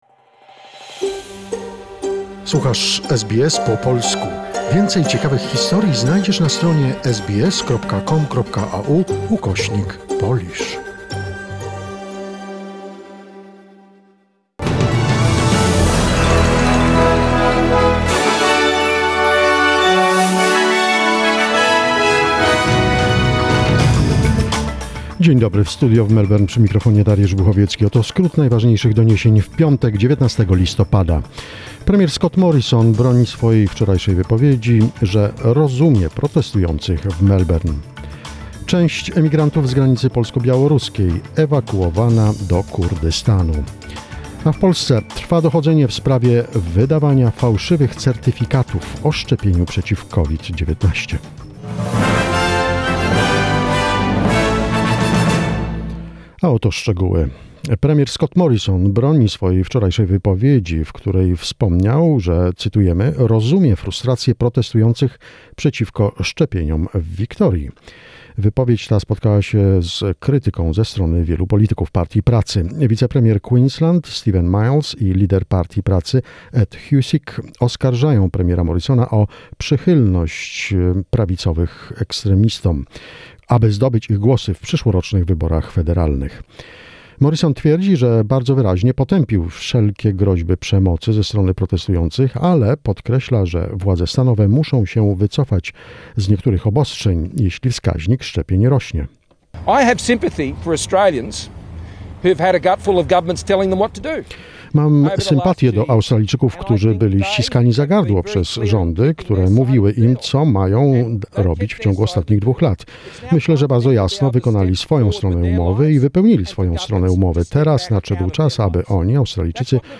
SBS News in Polish, 19 November 2021